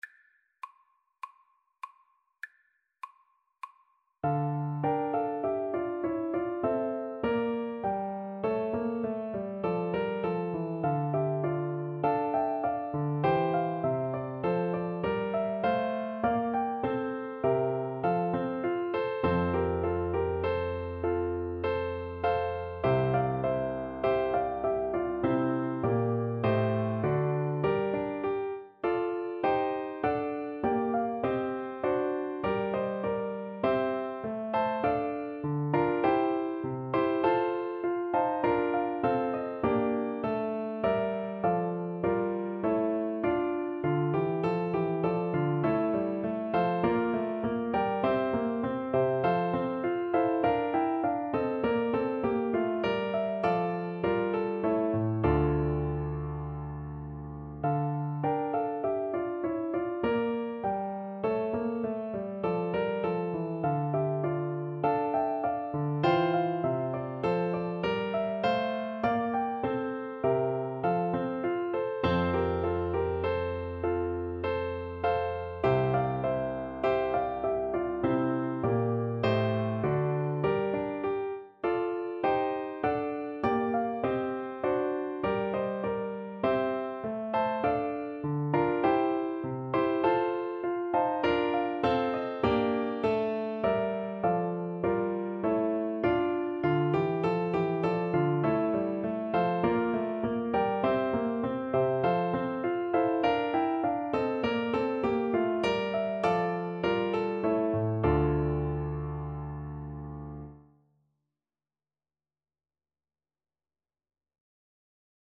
Play (or use space bar on your keyboard) Pause Music Playalong - Piano Accompaniment Playalong Band Accompaniment not yet available reset tempo print settings full screen
D minor (Sounding Pitch) E minor (Trumpet in Bb) (View more D minor Music for Trumpet )
Classical (View more Classical Trumpet Music)